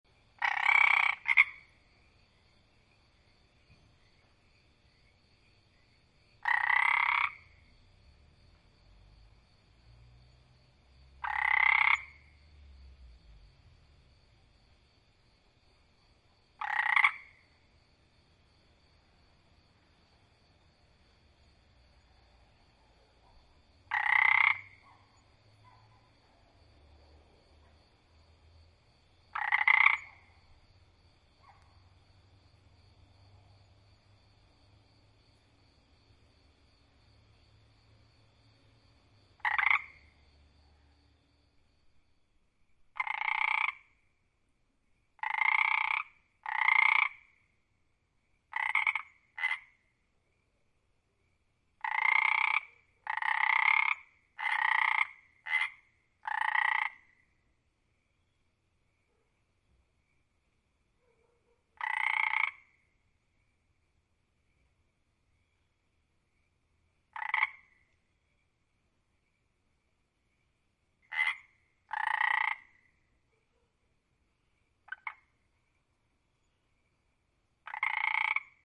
Download Frog sound effect for free.
Frog